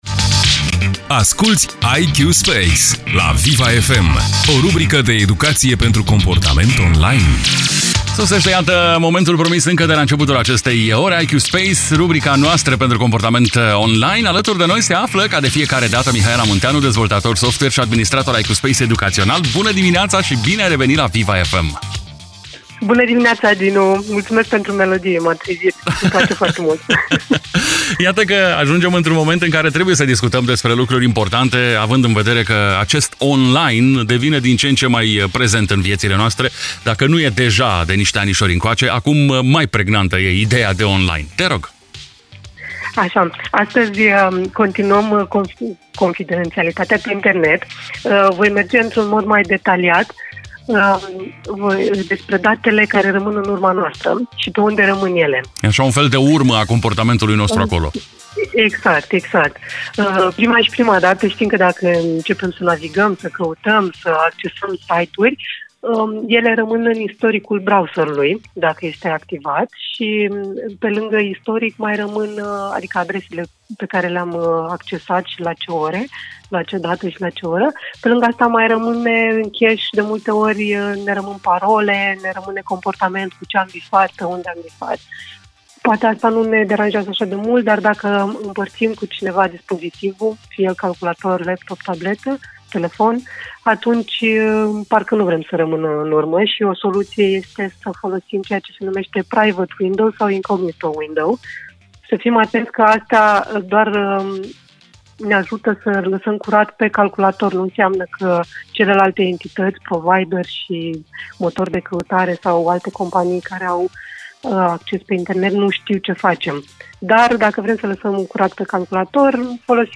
o rubrică de educație pentru comportament online